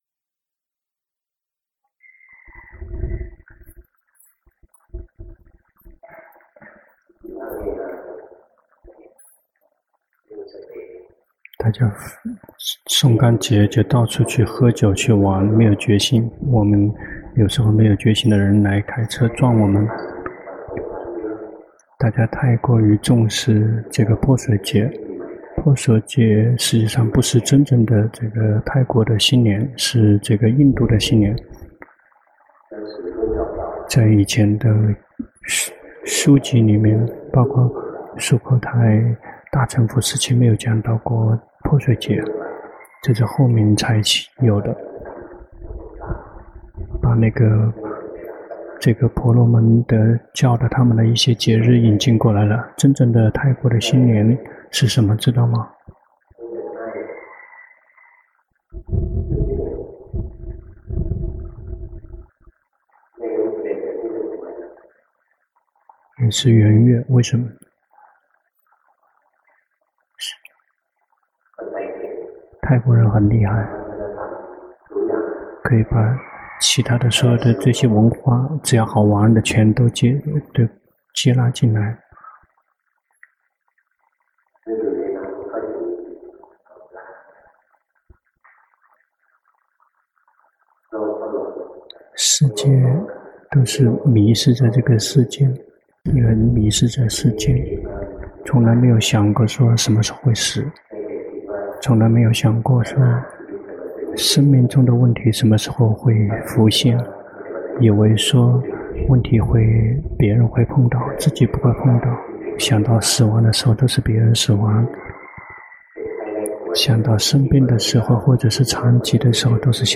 長篇法談｜隨時憶念死亡